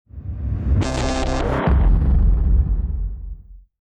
Royalty-free sound effects and music beds that came with iMovie and the iLife suite back in the day for use in videos.
Booming Reverse 01.m4a